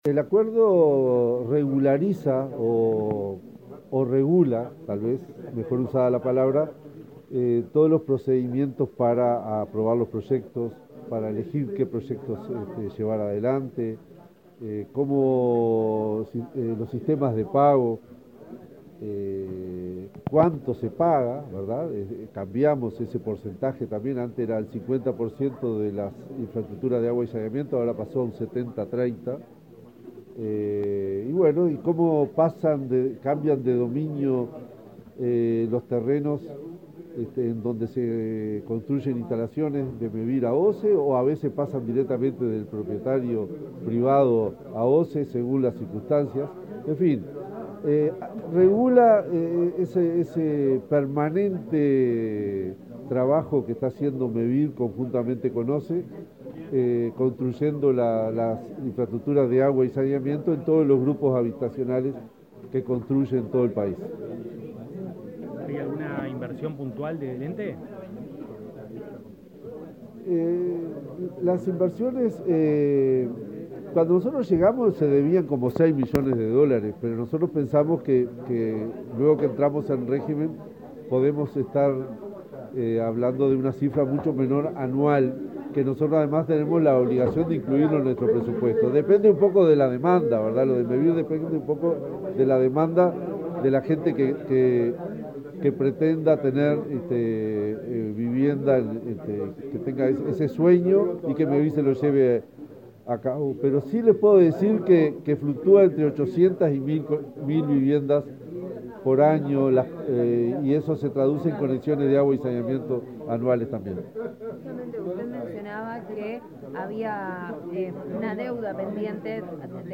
Declaraciones del presidente de OSE, Raúl Montero
Declaraciones del presidente de OSE, Raúl Montero 18/10/2023 Compartir Facebook X Copiar enlace WhatsApp LinkedIn La OSE y Mevir firmaron, este miércoles 18, un convenio para promover el saneamiento de viviendas en zonas rurales del país. El titular del ente, Raúl Montero, informó a la prensa acerca de las características del acuerdo.